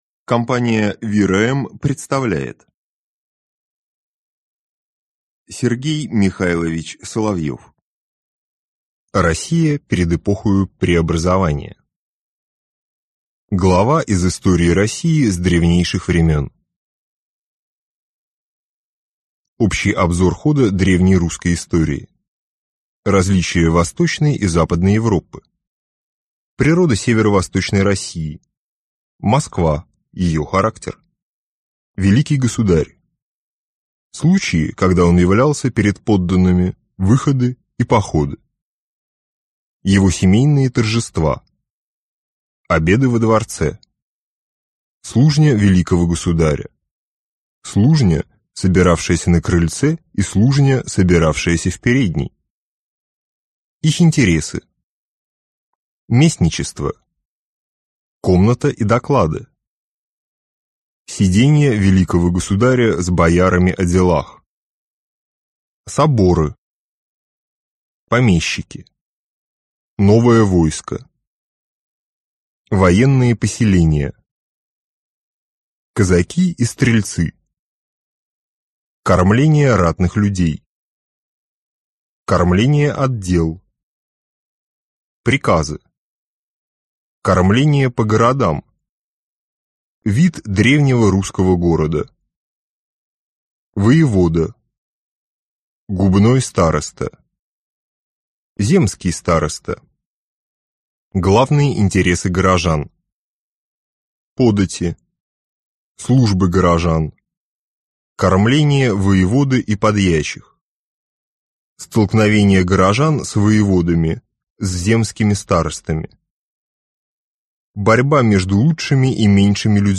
Аудиокнига Россия перед эпохою преобразования | Библиотека аудиокниг